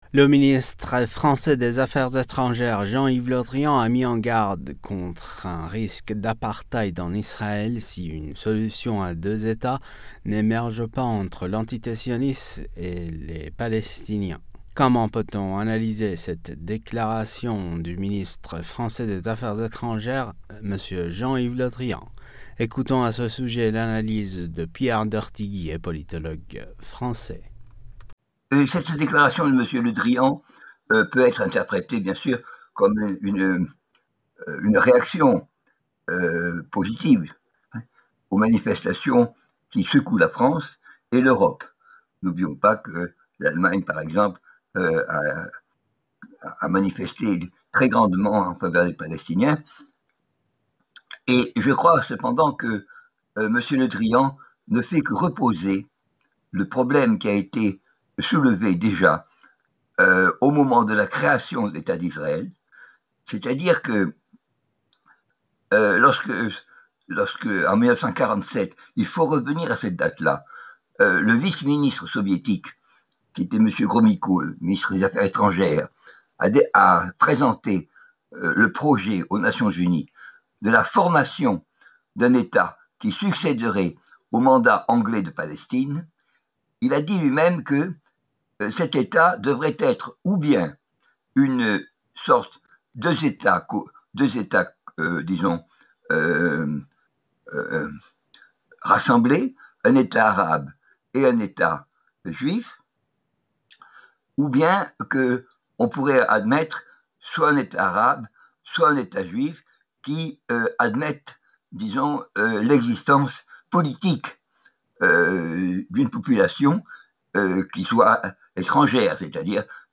Comment analyser cette déclaration de Jean-Yves Le Drian? Décryptage avec
politologue français.